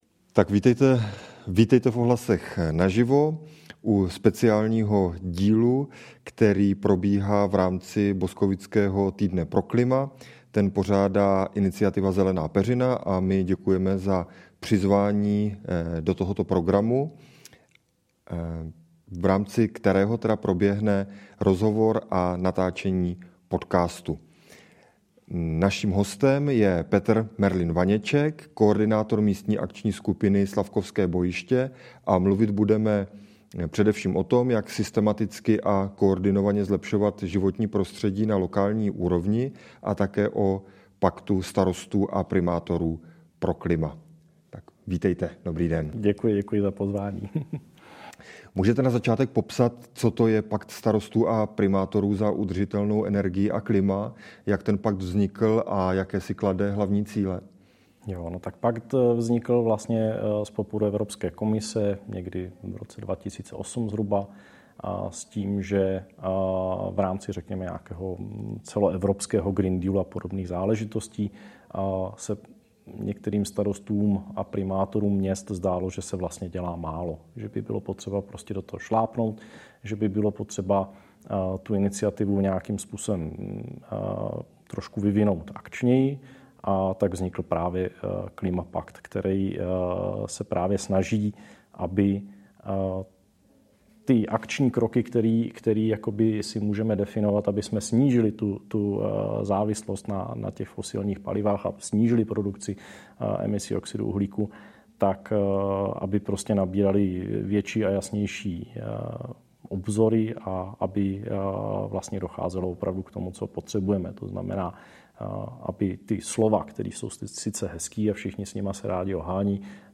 Mluvili jsme o tom, jak systematicky a koordinovaně zlepšovat životní prostředí na lokální úrovni a o Paktu starostů a primátorů pro klima. Živý rozhovor proběhl v rámci akce Týden pro klima, kterou v Boskovicích organizuje iniciativa Zelená peřina.